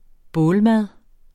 Udtale [ ˈbɔːl- ]